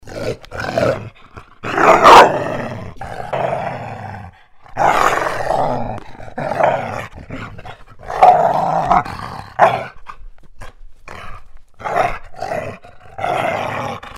Growl Sound
Dog-Growling.mp3